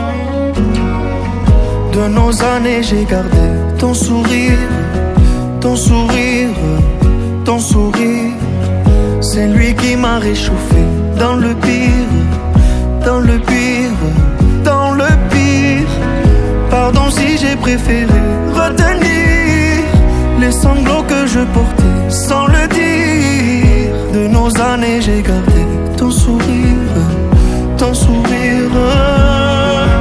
Musique audio